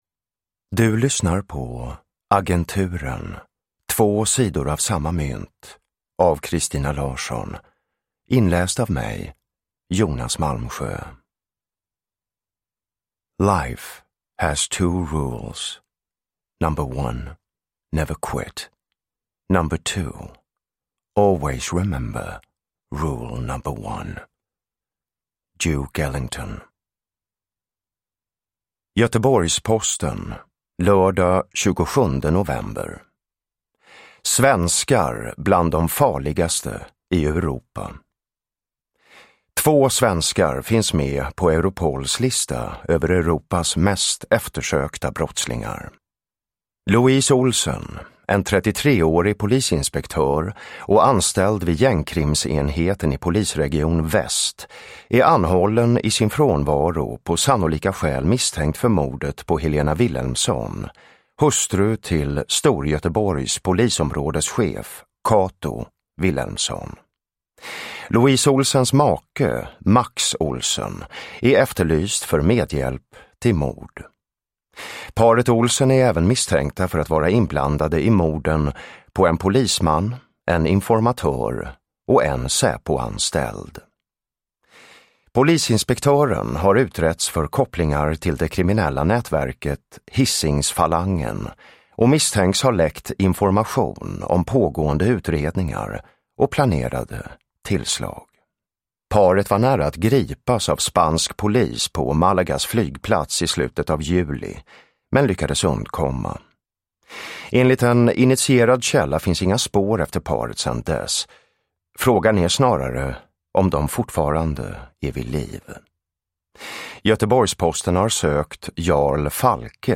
Två sidor av samma mynt – Ljudbok – Laddas ner
Uppläsare: Jonas Malmsjö